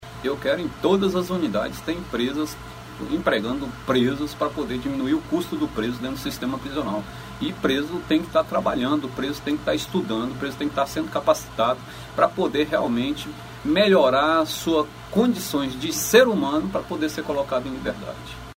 O secretário Mauro Albuquerque destaca que o objetivo do Governo do Ceará é levar empresas para dentro de todo o sistema penitenciário cearense.